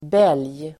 Ladda ner uttalet
Uttal: [bel:j]